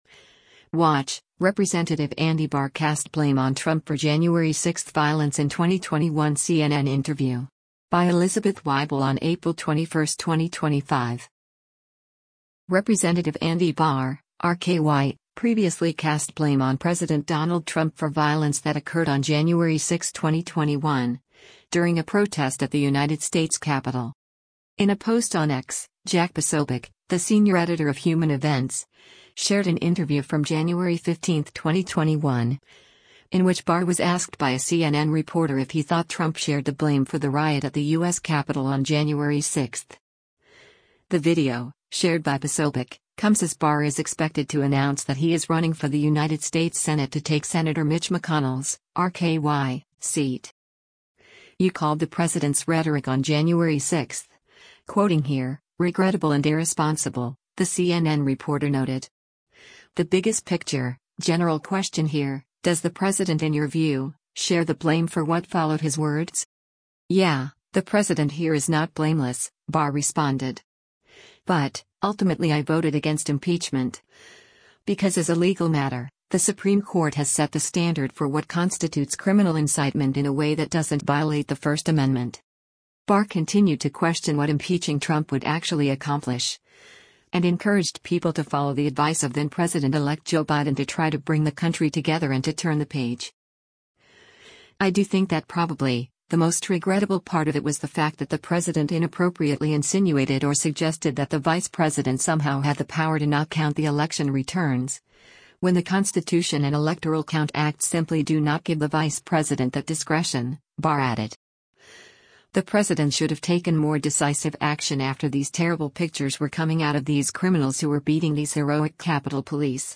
In a post on X, Jack Posobiec, the Senior Editor of Human Events, shared an interview from January 15, 2021, in which Barr was asked by a CNN reporter if he thought Trump shared “the blame for” the riot at the U.S. Capitol on January 6.